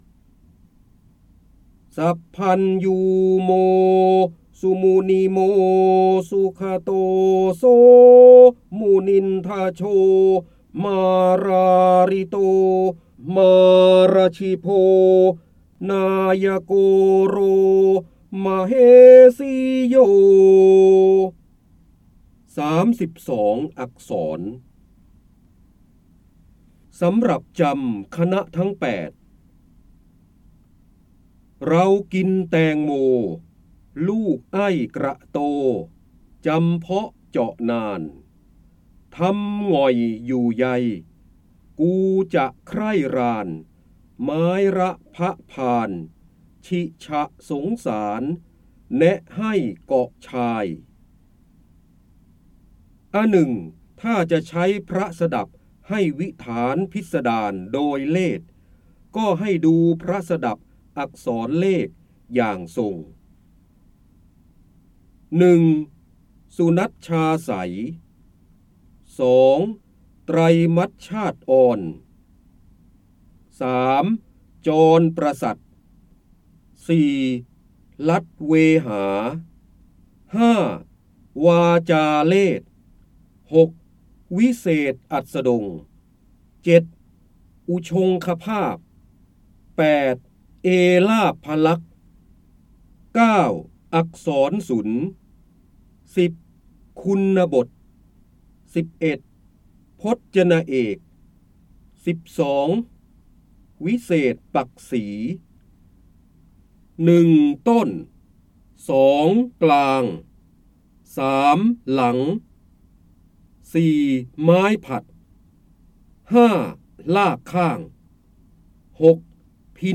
84 89 ตัวอย่าง ดาวน์โหลด ส่ง eCard เสียงบรรยายจากหนังสือ จินดามณี (พระโหราธิบดี) สำหรับจำคณทั้ง ๘ ฯ ได้รับใบอนุญาตภายใต้ ให้เผยแพร่-โดยต้องระบุที่มาแต่ห้ามดัดแปลงและห้ามใช้เพื่อการค้า 3.0 Thailand .